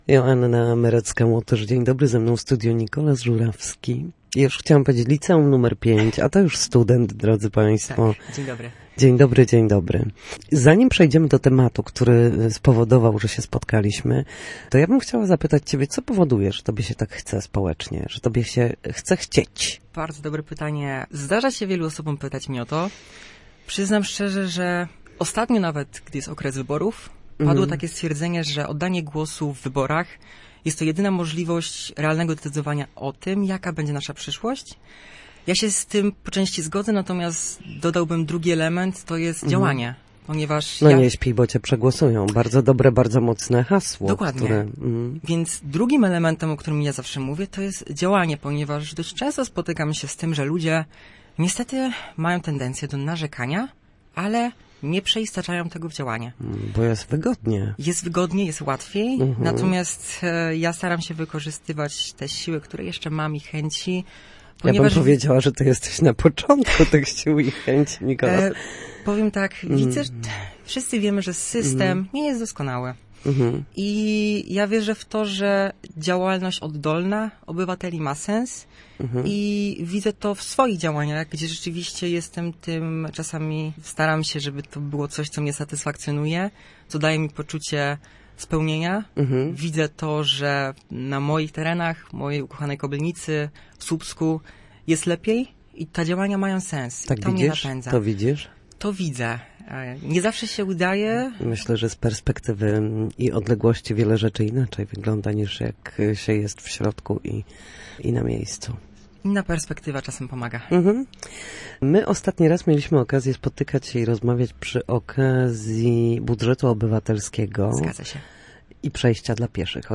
Jak edukować społeczeństwo, jak korzystać z defibrylatora i jak działać na rzecz wspólnoty? Posłuchaj całej rozmowy: